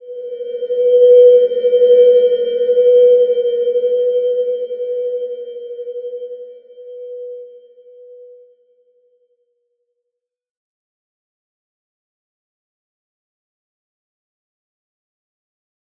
Slow-Distant-Chime-B4-mf.wav